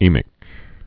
(ēmĭk)